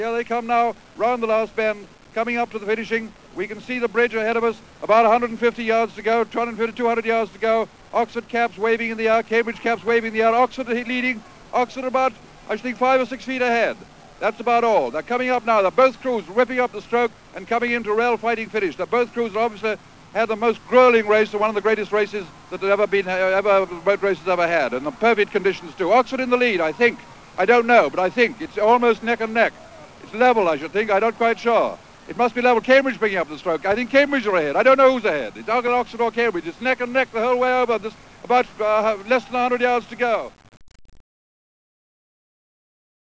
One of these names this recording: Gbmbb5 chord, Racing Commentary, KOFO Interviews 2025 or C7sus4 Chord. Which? Racing Commentary